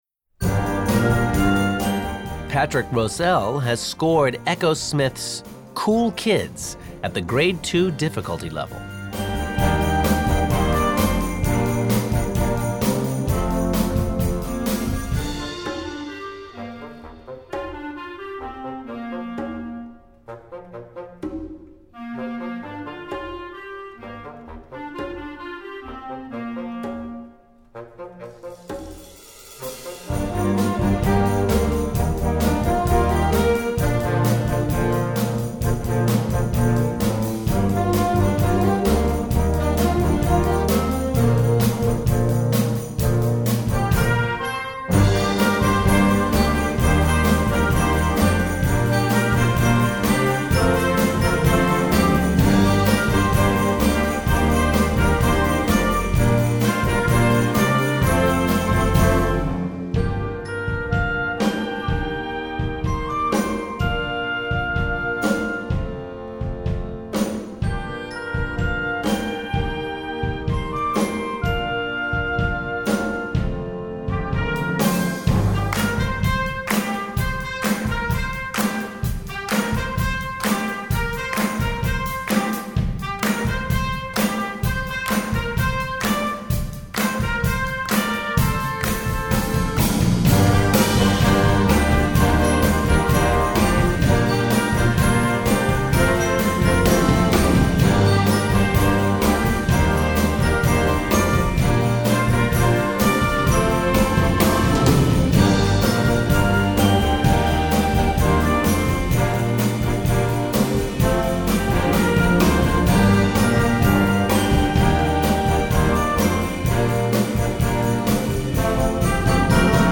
Besetzung: Blasorchester
This tune has an infectious beat and is climbing the charts.